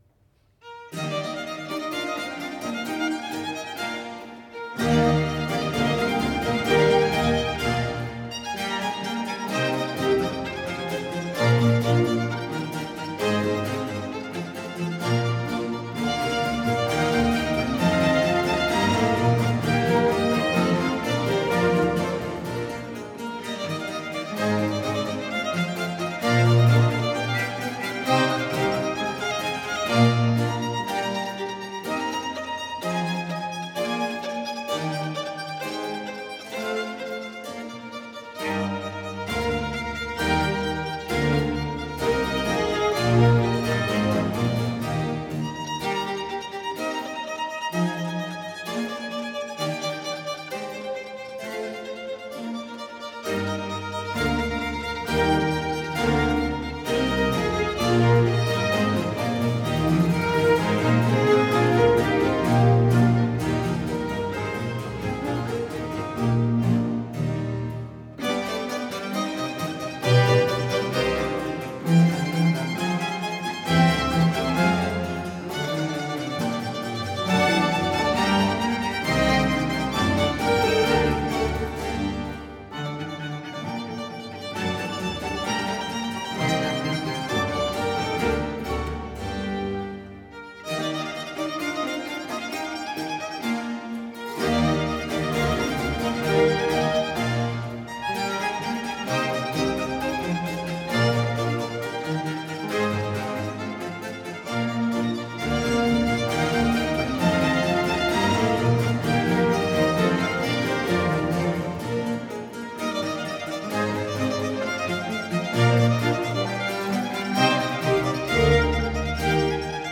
Preludio, adagio